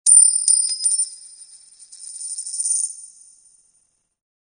Index of /phonetones/unzipped/Nokia/5070-Asia/Alert tones